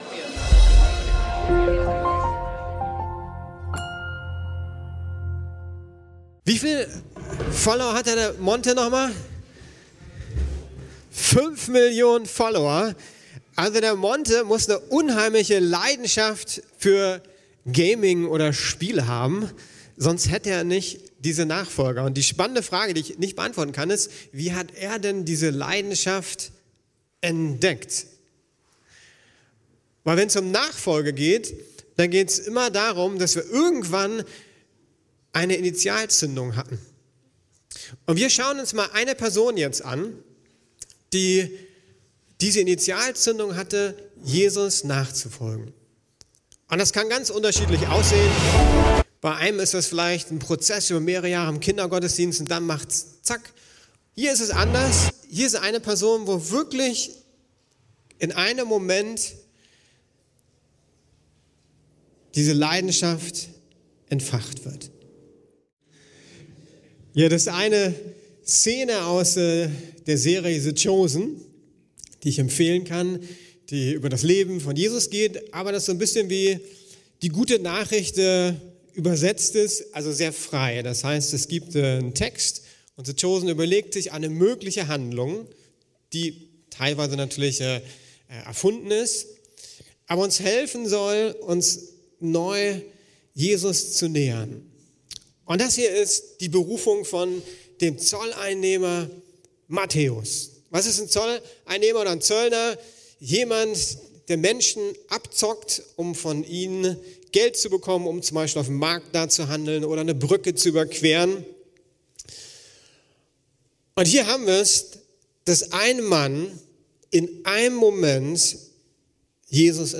Neu eröffnet: Gemeinde als Trainingsfeld ~ Predigten der LUKAS GEMEINDE Podcast